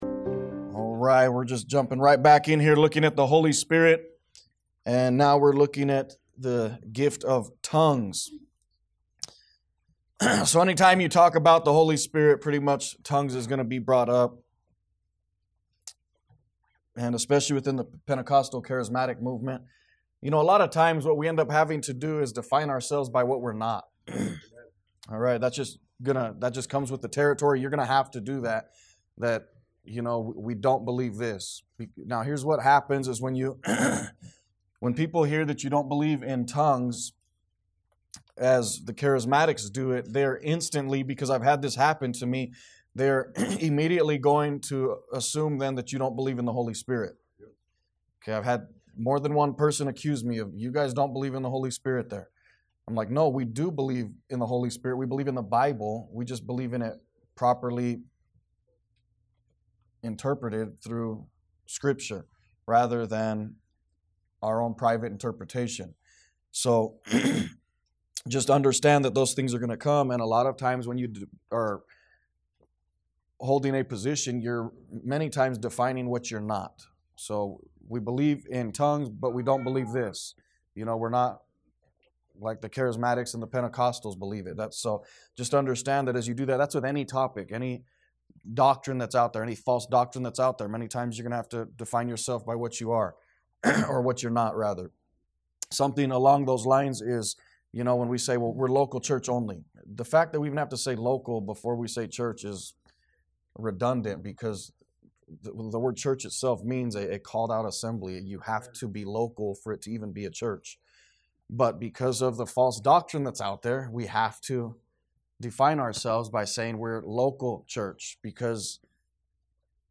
A message from the series "The Sermon On The Mount."